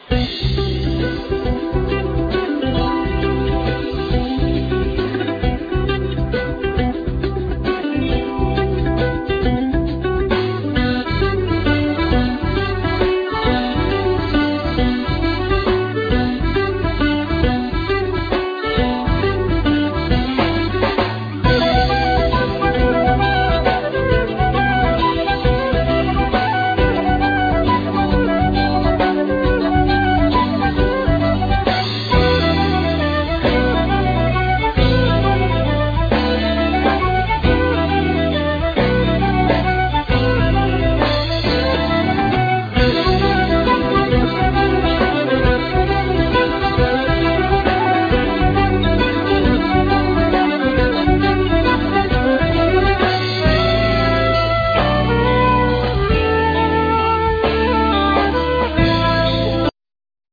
Flute,Tin & Low whistles,Bodhram
Guitar,Bocoder voice
Mandlin,Bouzoki,Banjo,Sitar,Percussions,Programming
El.bass
Drums
Accordion
Keyboards
Violin
Vocal,African percussions